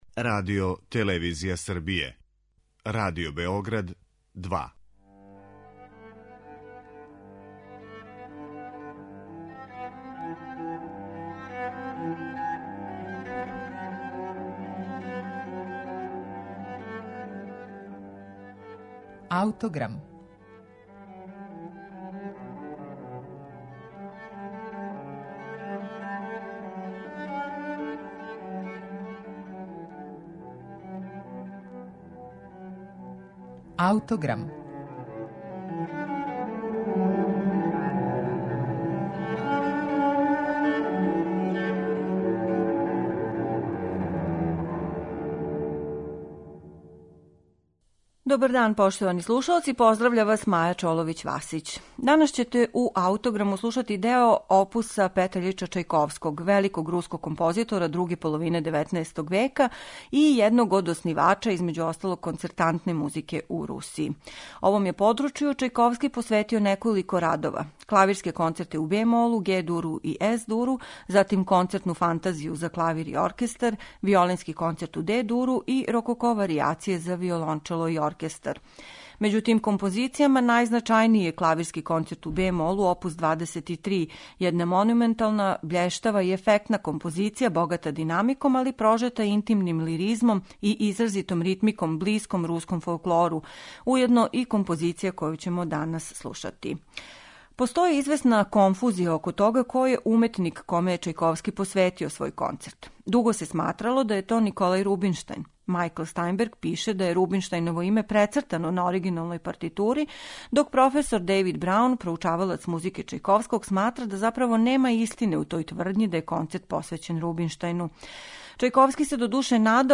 Први клавирски концерт Чајковског
Дело које је Ханс фон Билов премијерно извео 1875. године у Бостону и већ тада описао као изузетно оригинално и племенито , слушаћете у легендарном извођењу Иве Погорелића и Лондонског сифмонисјког оркестра.